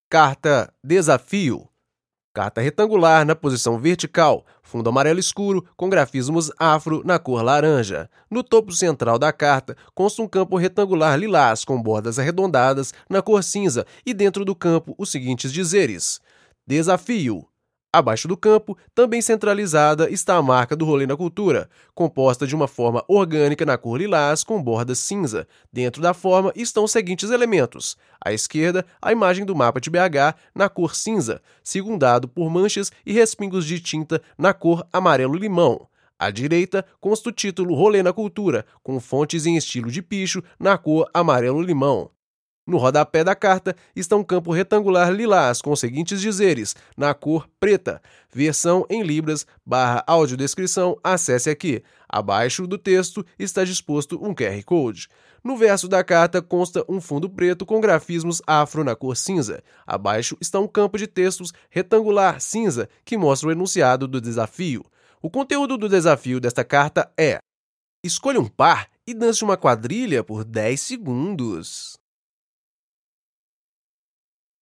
Audiodescrição: